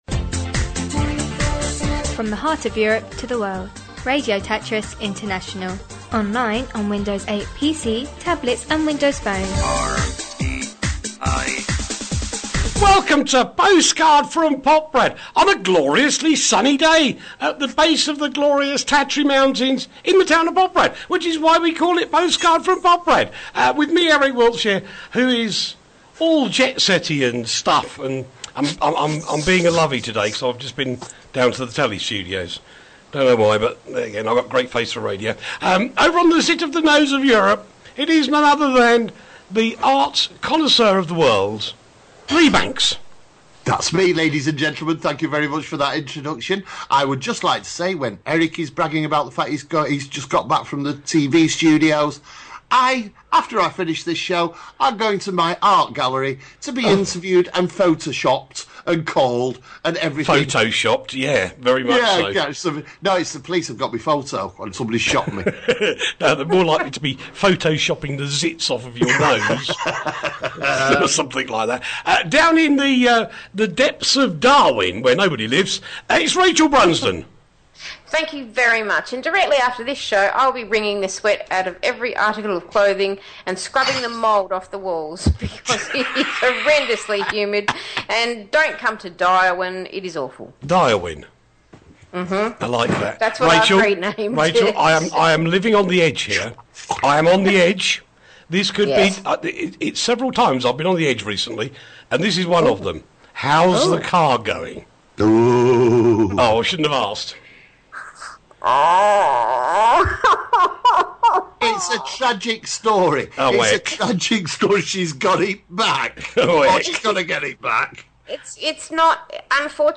From The Heart Of Europe To The World, Radio Tatras International, Online, On Windows 8 PC, Tablet and Windows Phone.